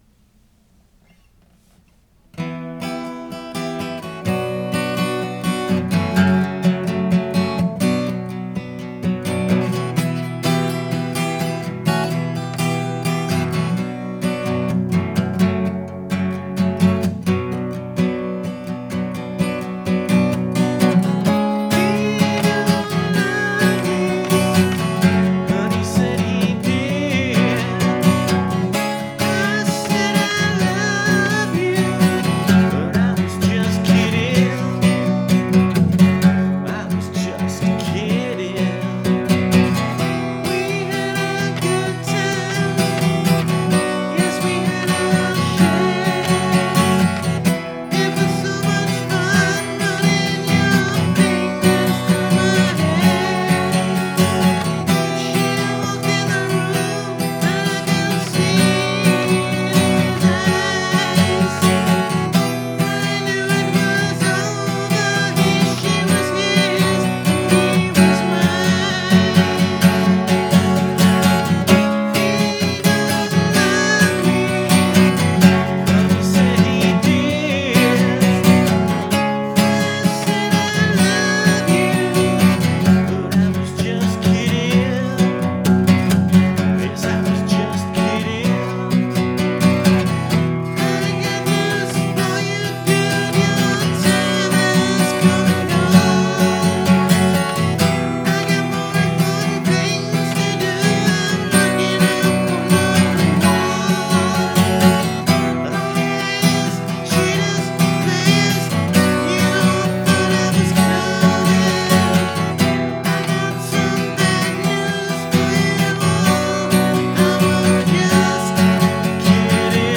Although initially recorded on an old Tascam 2-track recorder, (or my phone), in my living room, with some catchy melodies and heartfelt lyrics, these demos encapsulate the essence of my style of rock music.
Again trying to sing lyrics from a female perspective. Oh boy am I stretching my voice for a falsetto and an off key one at that.